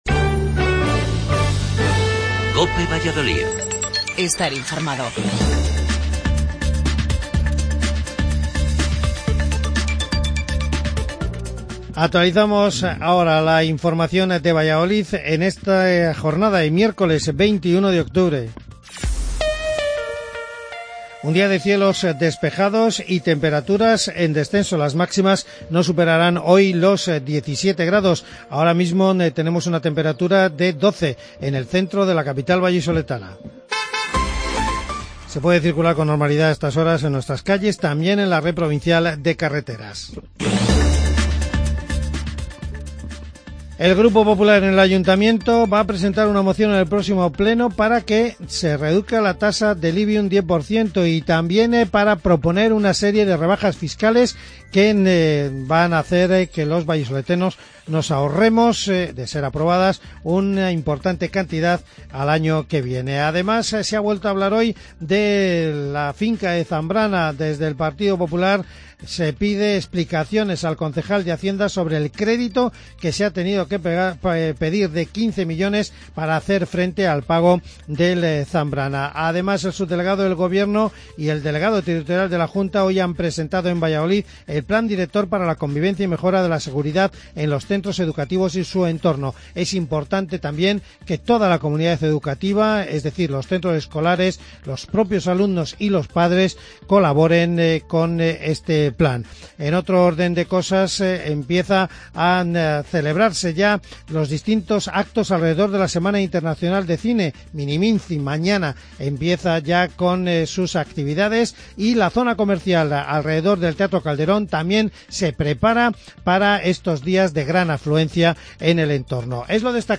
AUDIO: Informativo local